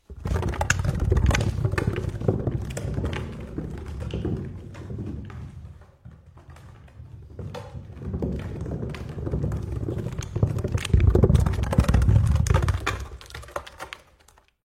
Tiếng Bánh xe gỗ lăn… (Mẫu số 2)
Thể loại: Tiếng xe cộ
Description: Âm thanh tiếng bánh xe gỗ lăn vang lên đều đặn, khô khốc và cọt kẹt, gợi nhớ hình ảnh những chiếc xe ngựa cũ kỹ, xe đẩy hàng rong lăn trên con đường đá xưa. Tiếng kẽo kẹt, lăn nặng nề của bánh xe mòn, tạo nên cảm giác hoài cổ, mộc mạc và chân thật.
tieng-banh-xe-go-lan-mau-so-2-www_tiengdong_com.mp3